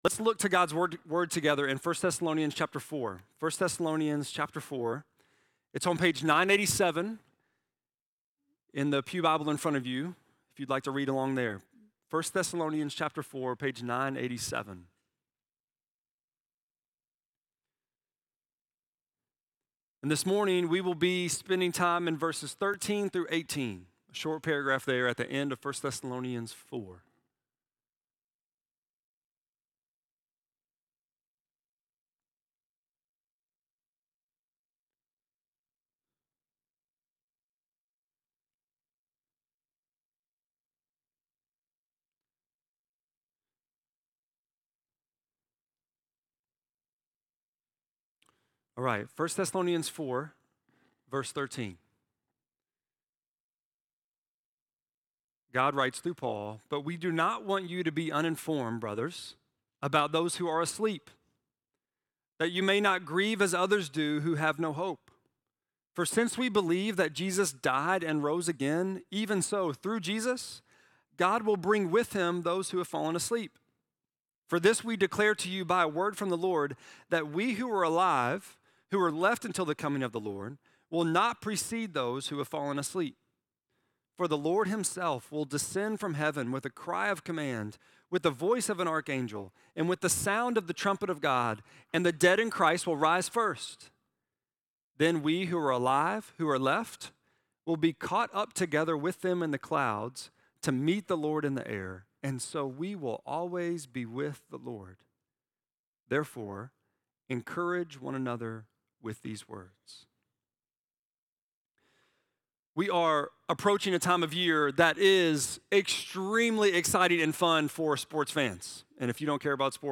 10.9-sermon.mp3